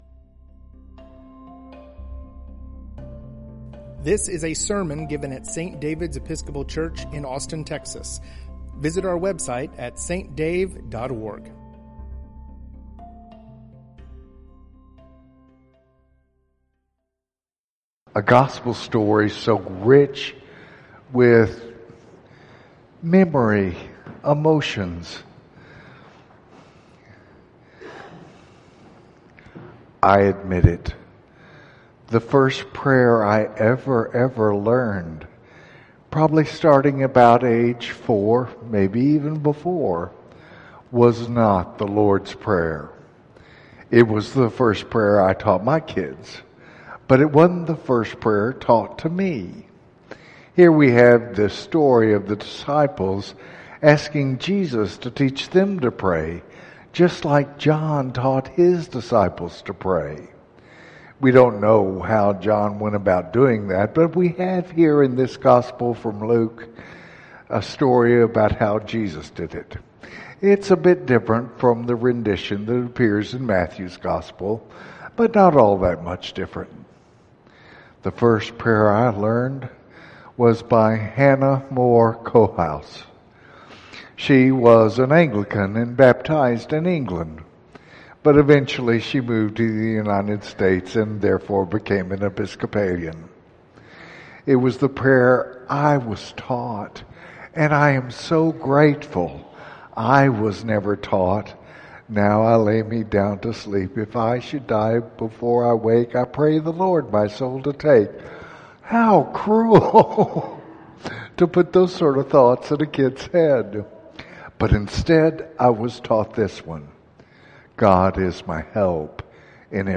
Sermon
The Abbey at St. David's